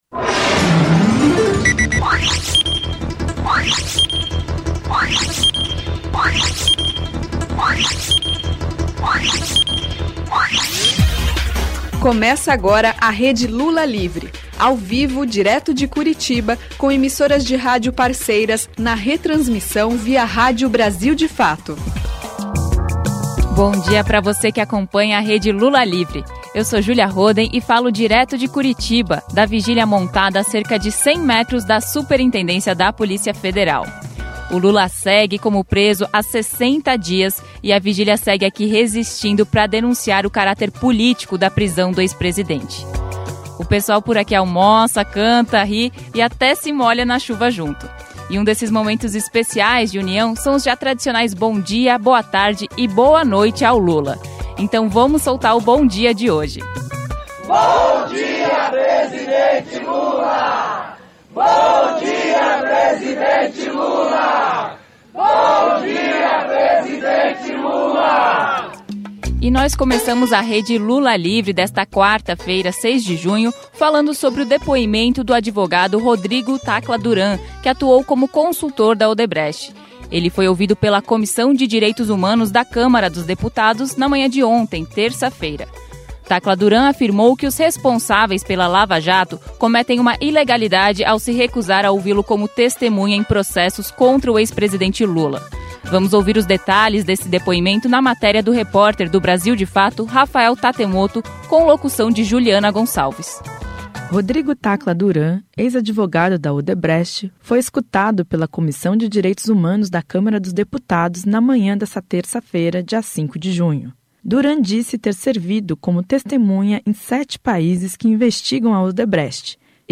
Na edição de hoje, você ouve a entrevista exclusiva de Frei Betto. O religioso visitou o Lula nessa semana e conta como está sendo a rotina do ex-presidente na prisão.